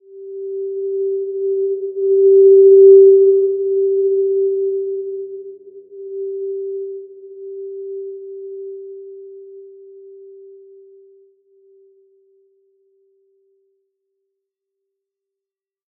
Simple-Glow-G4-p.wav